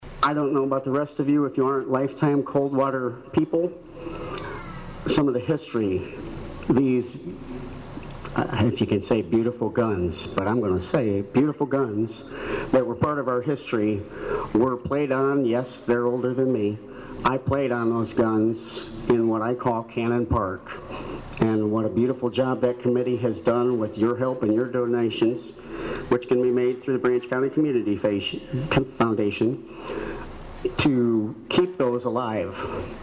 Coldwater’s Memorial Day ceremony was held in the newly renovated Four Corners Park in front of a large gathering on a sun splashed morning.